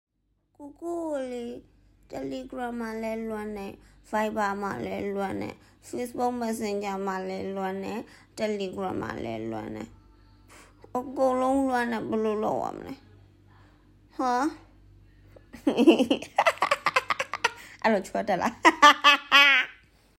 ရီသံနဲ့တင်ဇတ်သိမ်း😂😂